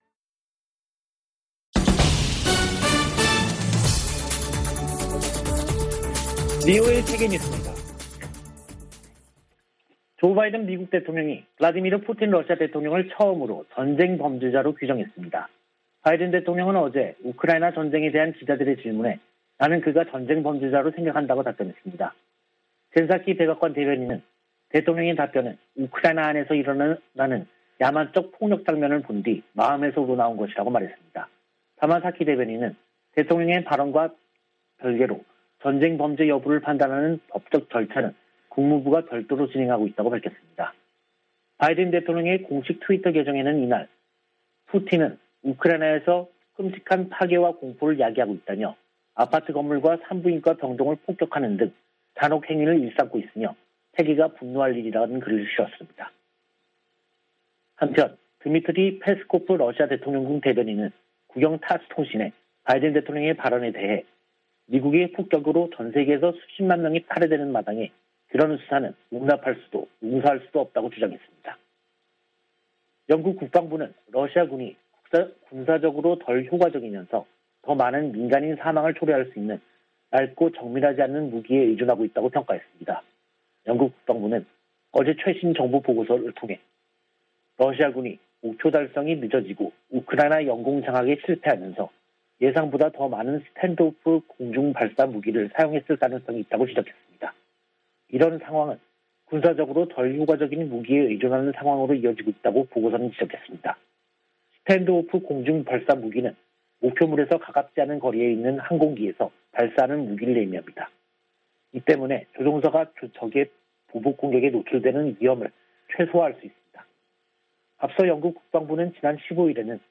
VOA 한국어 간판 뉴스 프로그램 '뉴스 투데이', 2022년 3월 17일 3부 방송입니다. 미군 당국은 한반도의 어떤 위기에도 대응 준비가 돼 있다며, 억지력 기초는 준비태세라고 강조했습니다. 북한은 16일 탄도미사일 발사 실패에 침묵하고 있습니다. 미국의 전문가들은 북한의 지속적인 미사일 발사에 미한이 억지력 강화에 주력하면서 한반도 긴장이 고조될 것이라고 관측했습니다.